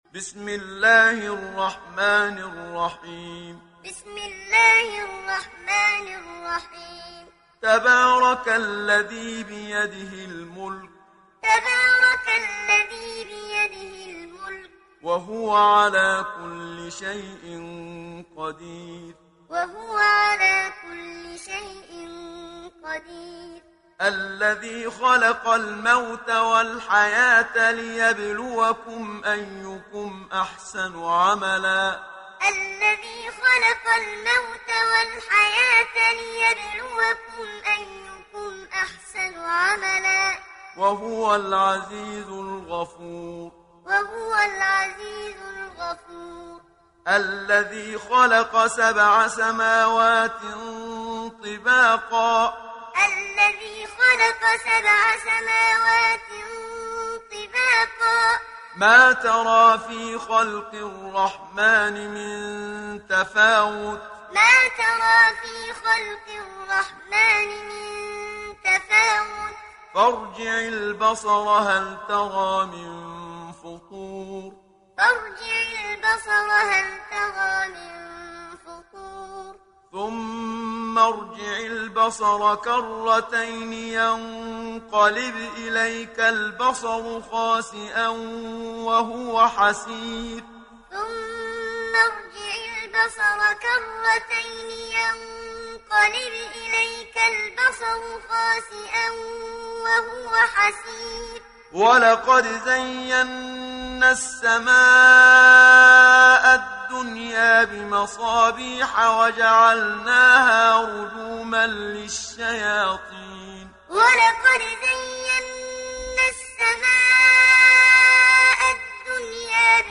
تحميل سورة الملك mp3 بصوت محمد صديق المنشاوي معلم برواية حفص عن عاصم, تحميل استماع القرآن الكريم على الجوال mp3 كاملا بروابط مباشرة وسريعة
تحميل سورة الملك محمد صديق المنشاوي معلم